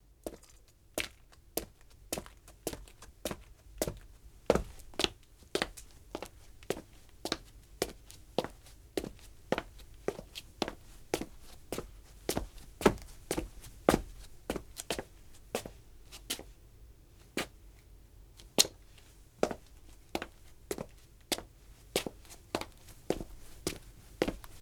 Pasos de un hombre a velocidad normal
Sonidos: Especiales
Sonidos: Acciones humanas